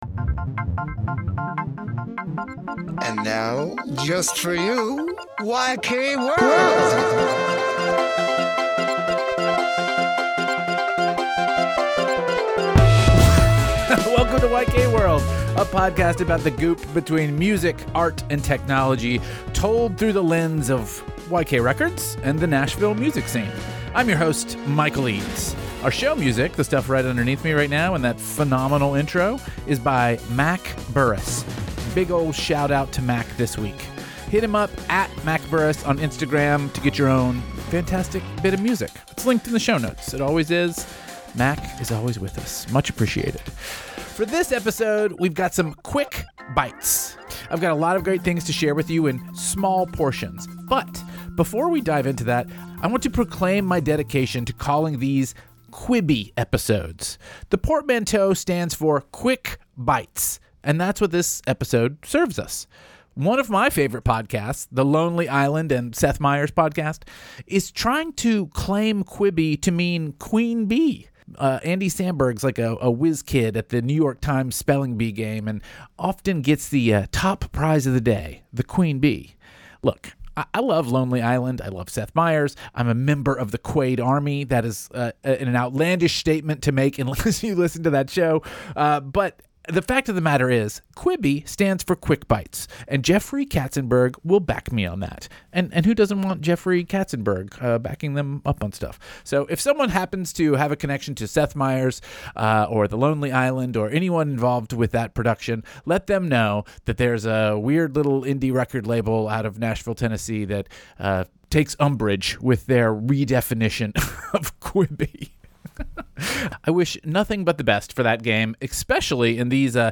Theme song and episode music